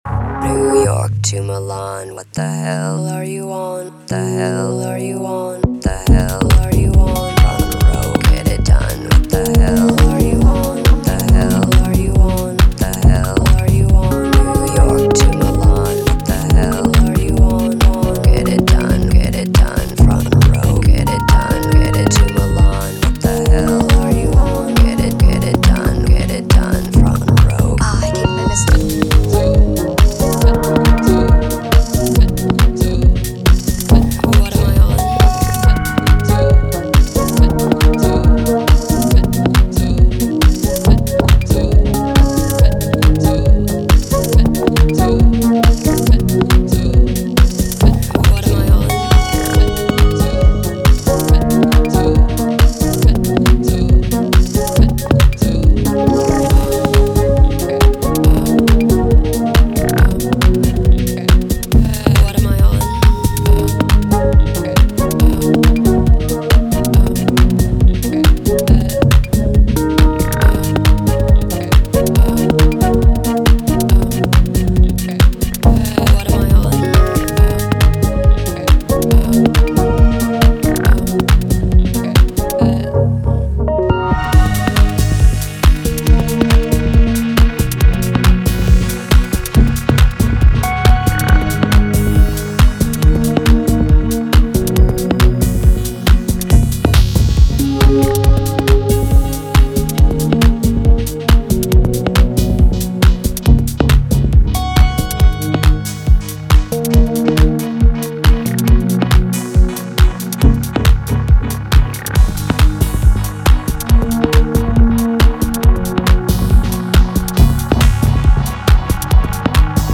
a collection of 4 tracks fine-tuned for the chaotic club.
comfortably hangs back